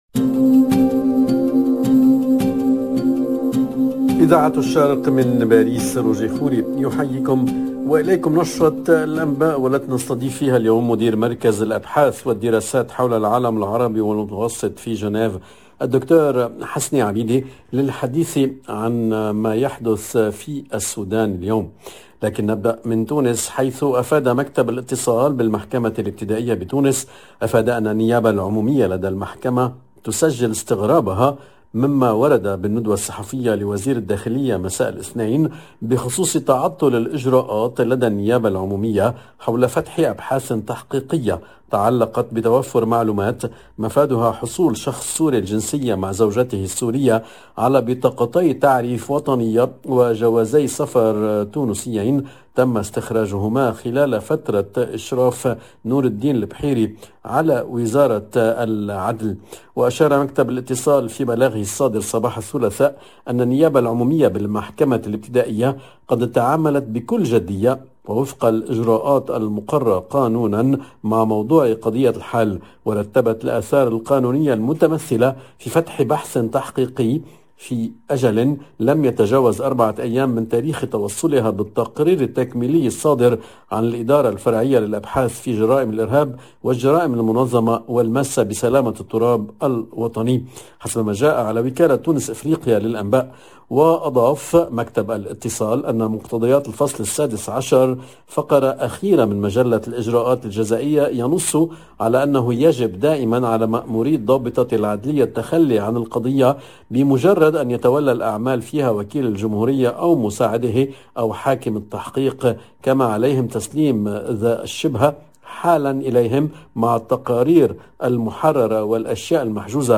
LE JOURNAL DU SOIR EN LANGUE ARABE DU 4/01/22